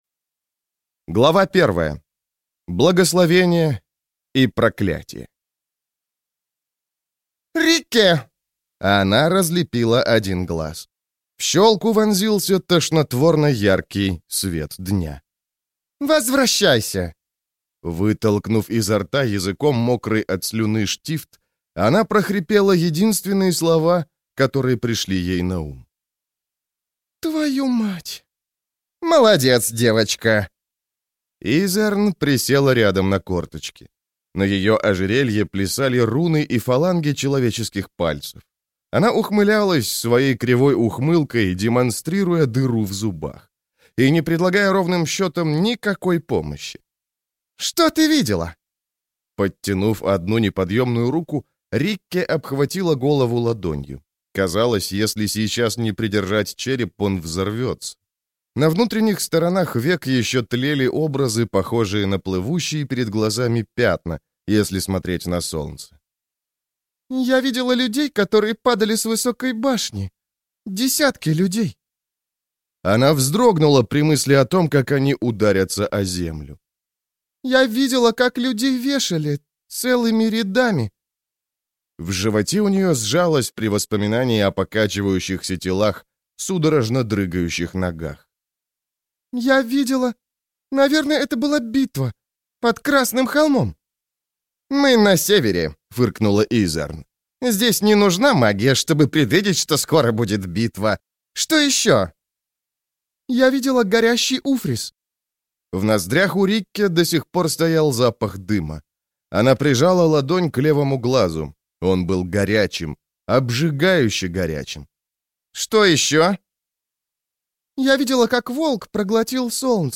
Аудиокнига Немного ненависти - купить, скачать и слушать онлайн | КнигоПоиск